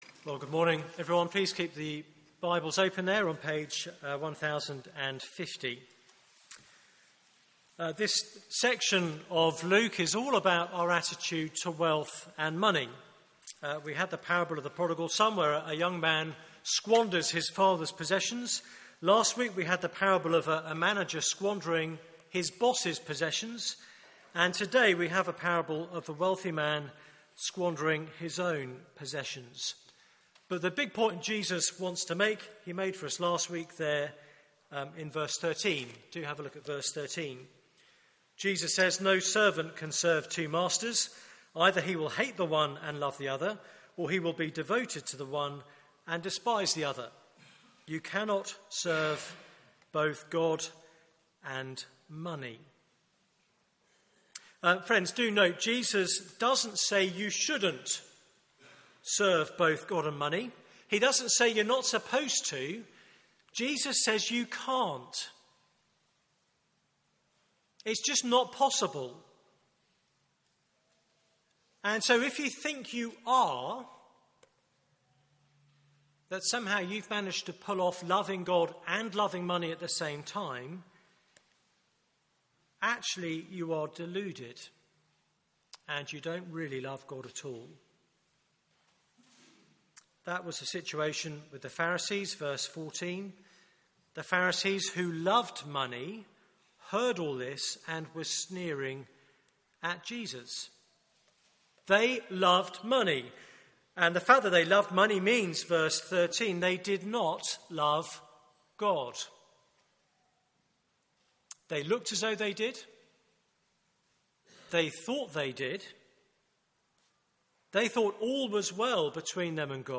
Media for 9:15am Service on Sun 26th Jul 2015
Theme: Riches to rags Sermon